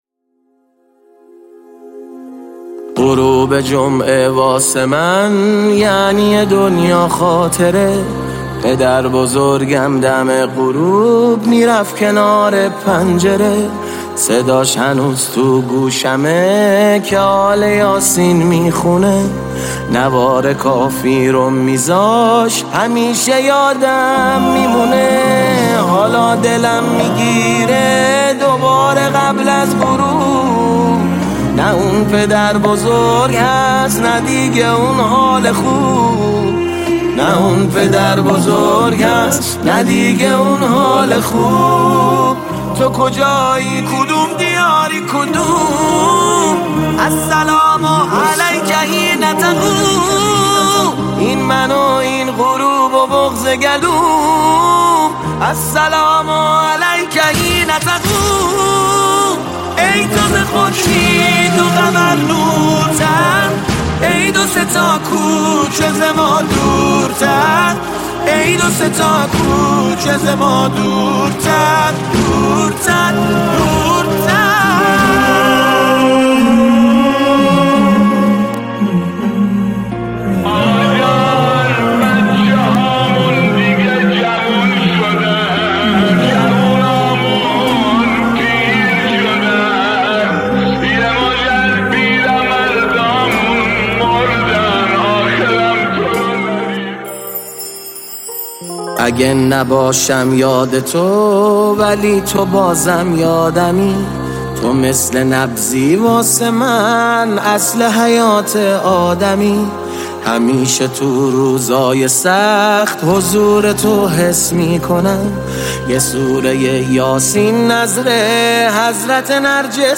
نماهنگ مهدوی